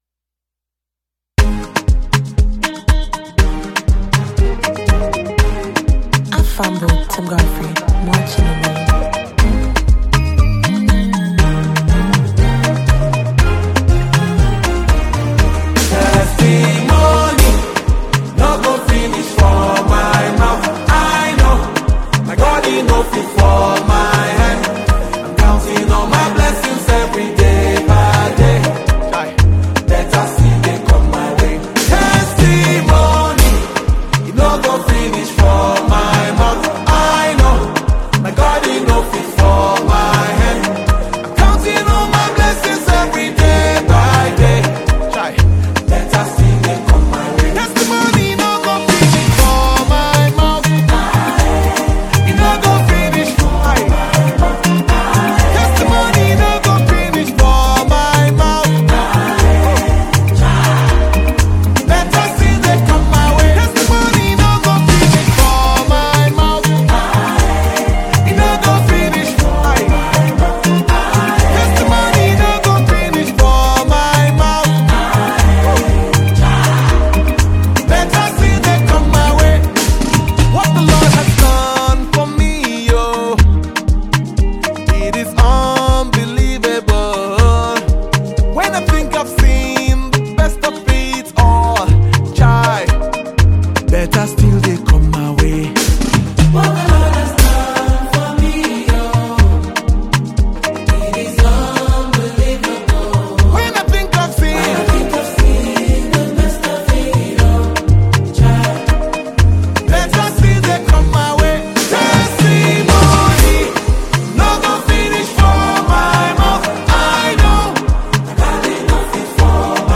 gospel music
studio project